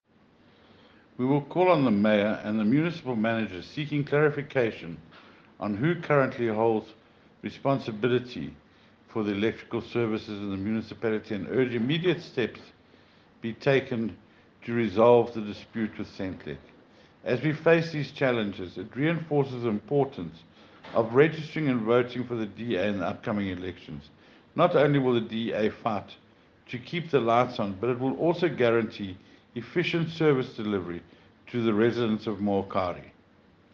Sesotho soundbites by Karabo Khakhau MP.